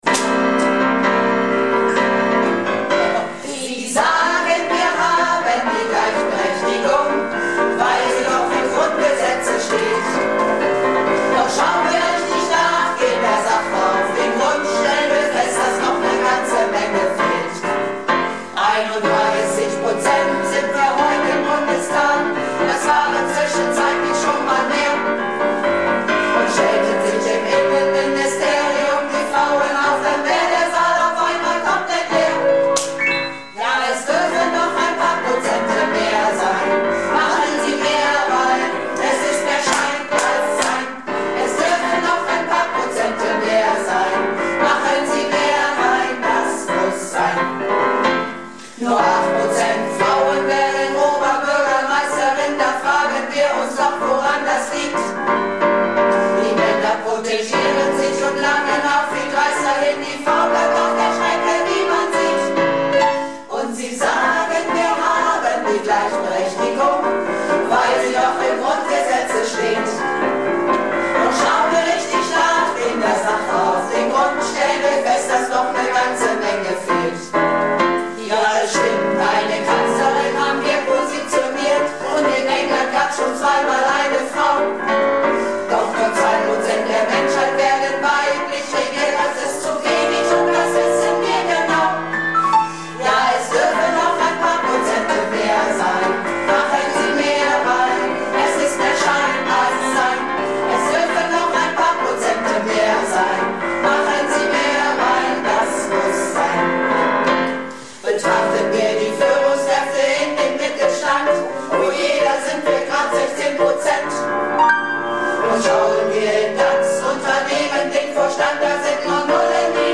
Projektchor "Keine Wahl ist keine Wahl" - Probe am 29.05.19